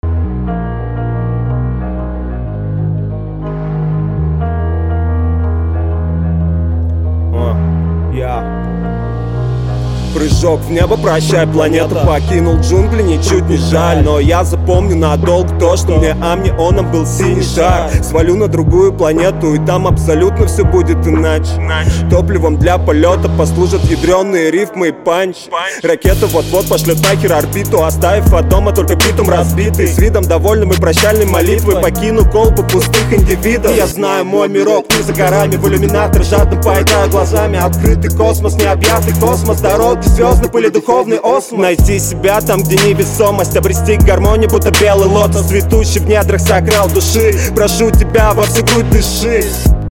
Полумодное исполнение не цепляет, даже больше раздражает.